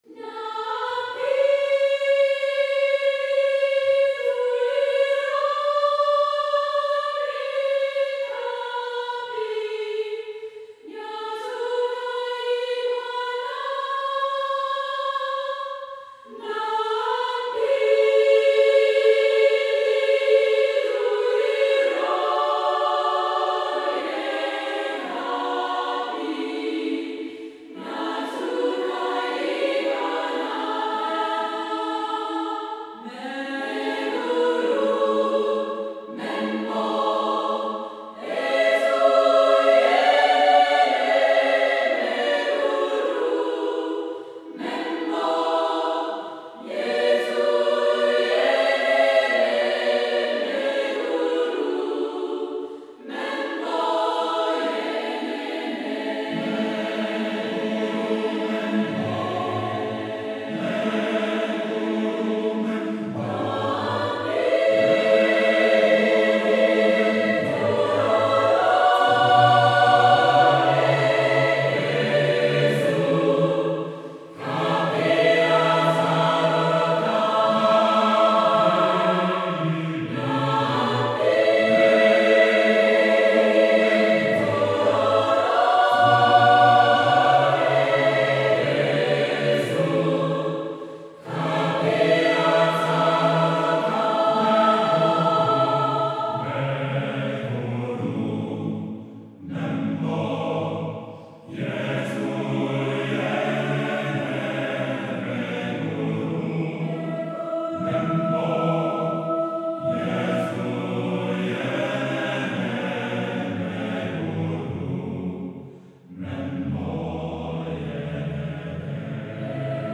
Voicing: SATB divisi